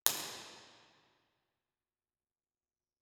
Description: Semi-reverberant octagonal House of Worship (HOW)
Room Impulse Responses